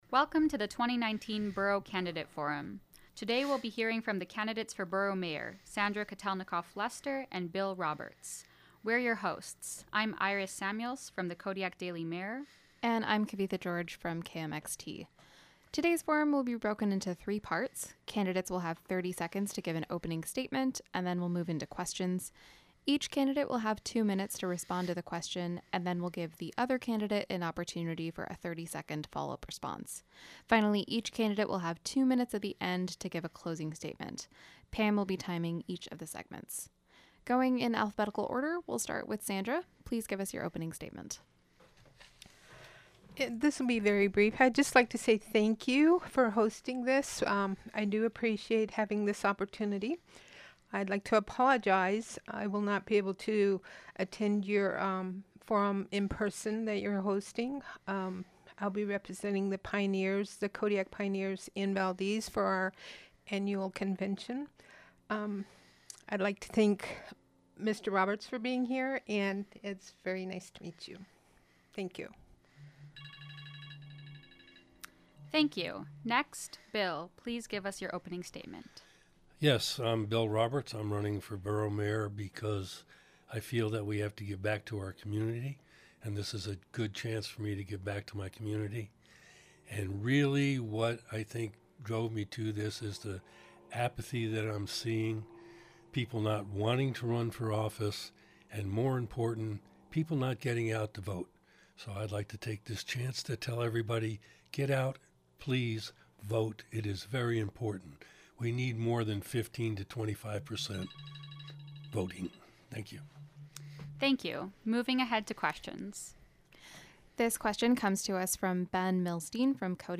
School Board Forum, Sept. 25
Candidates for school board and Borough Assembly participated in a forum on Wednesday night in Borough Assembly Chambers.